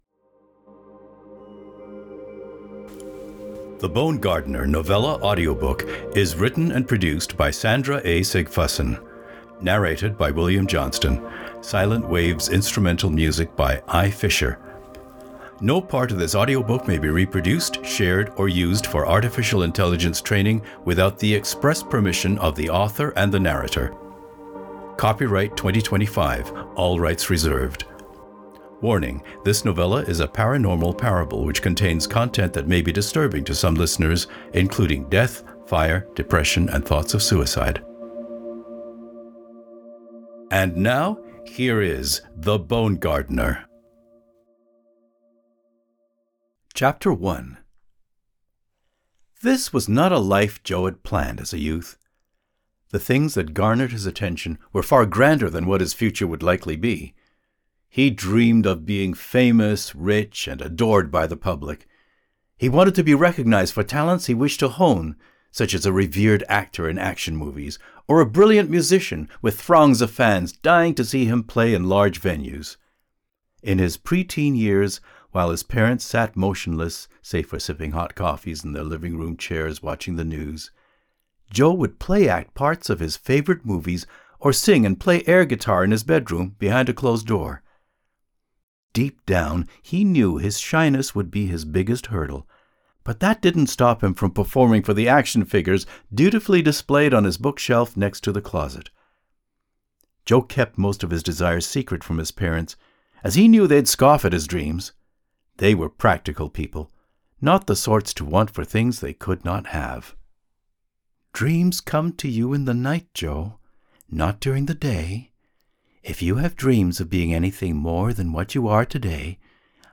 The Bone Gardener audiobook is available to read/listen to for free. It is a one-hour long story of Joe Arthur White who discovers that sometimes you reap far more than you sow – especially when you live in a town called Garden of Eden.
TheBoneGardener_Combined.mp3